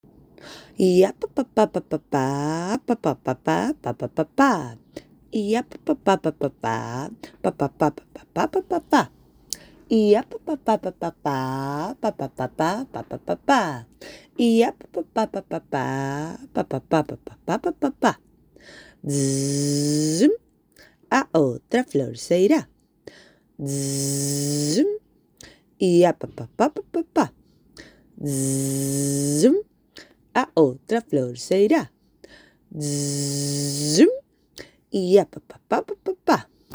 Triple.